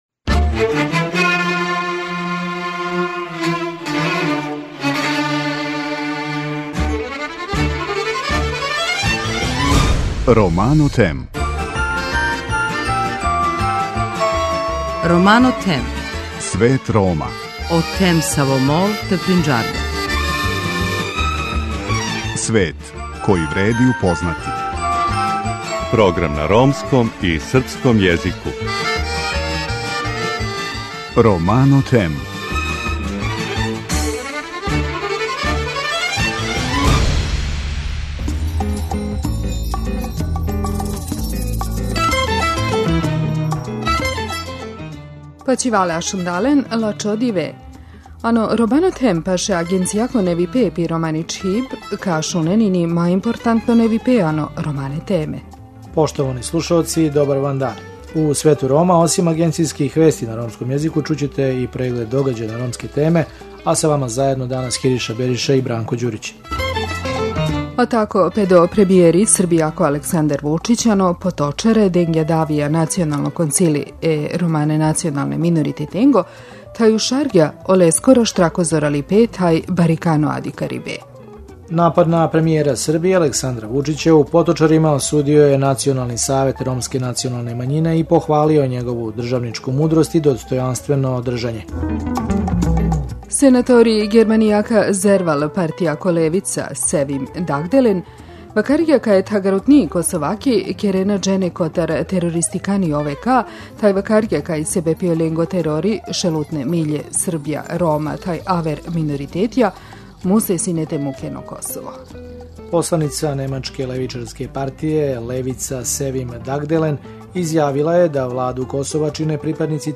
Колеге из Ромске редакције Радио Косова, припремили репортажу.